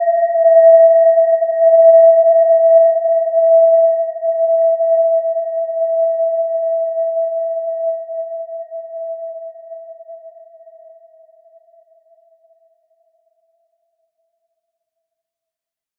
Gentle-Metallic-3-E5-mf.wav